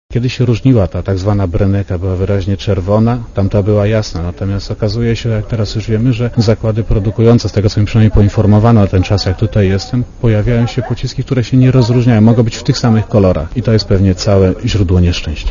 Mówi komendant główny policji, Leszek Szreder